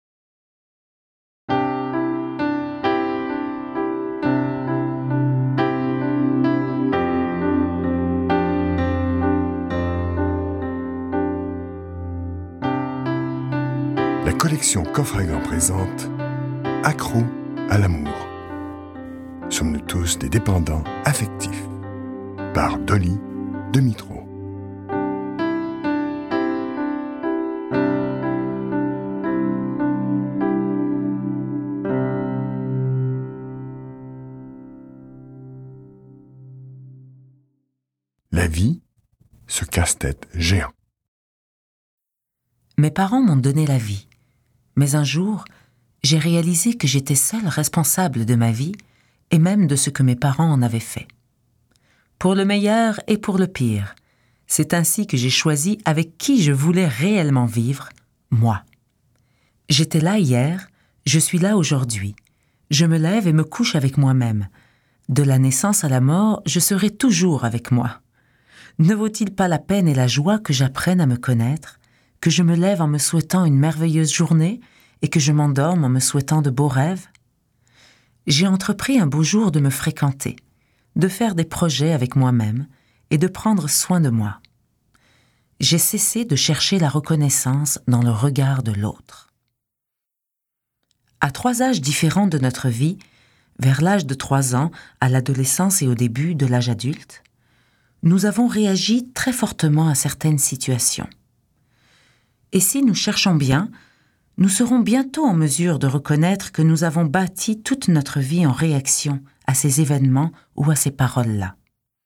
Ce livre audio inspirant, peut vous aider à prendre conscience de votre propre valeur et il a le pouvoir de changer votre vie.